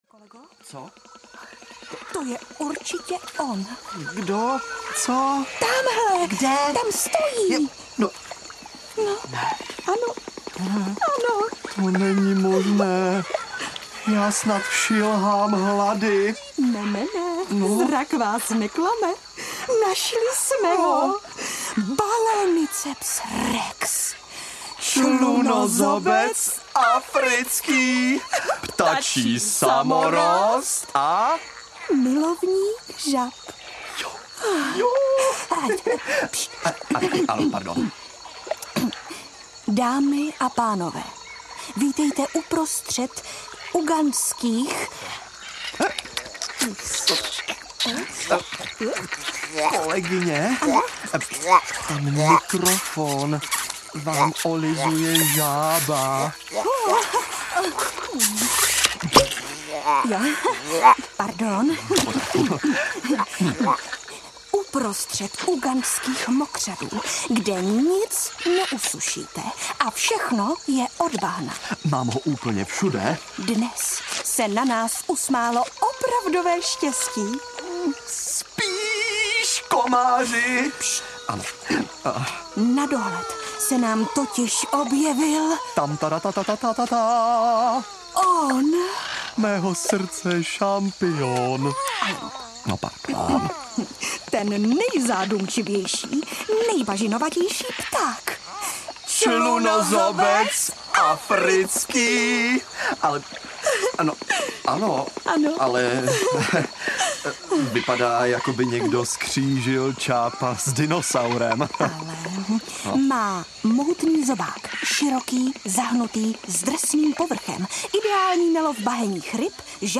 Hurvínek a nezvaný host audiokniha
Ukázka z knihy
Jako speciální host v roli bratrance Pepina účinkuje Jaromír Hanzlík.